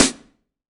SNARE 056.wav